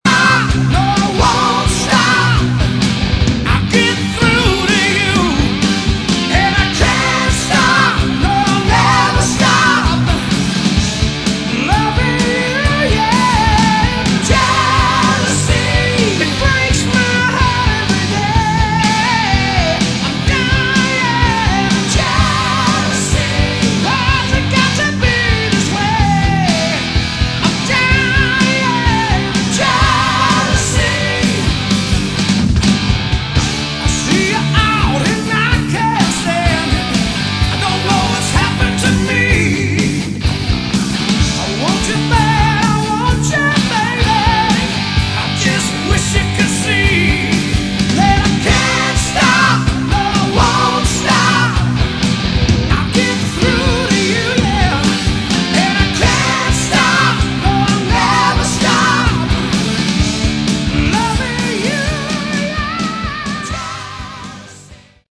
Category: Melodic Rock
vocals
keyboards, guitar
bass
drums